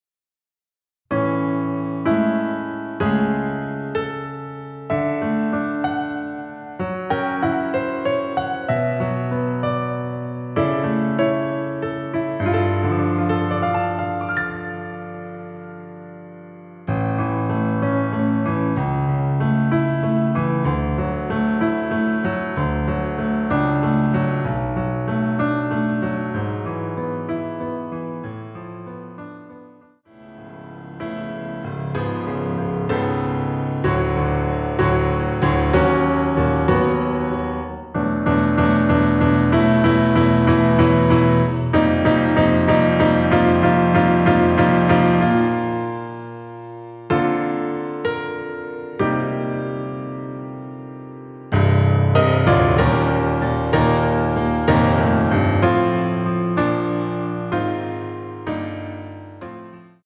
반주가 피아노 하나만으로 되어 있습니다.(아래의 유튜브 동영상 참조)
원키에서(-2)내린 피아노 버전 MR입니다.
앞부분30초, 뒷부분30초씩 편집해서 올려 드리고 있습니다.